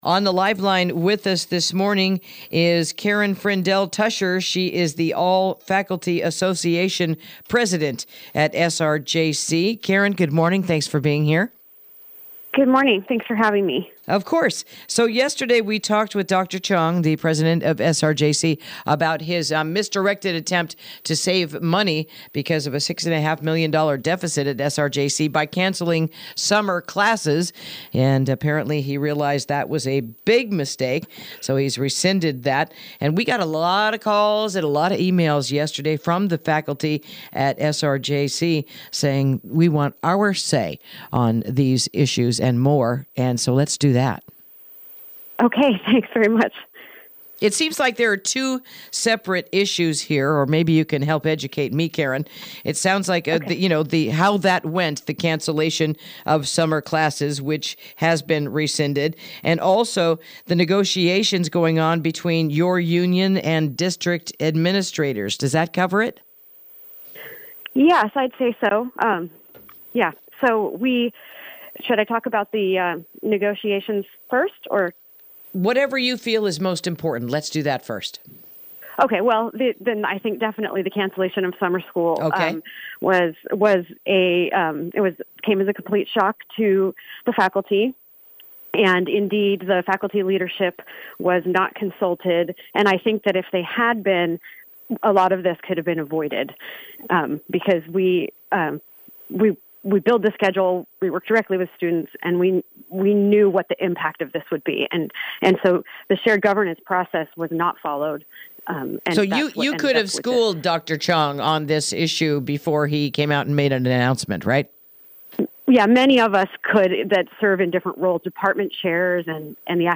Interview: SRJC Faculty Responds to the Announced Cancellation of Summer Classes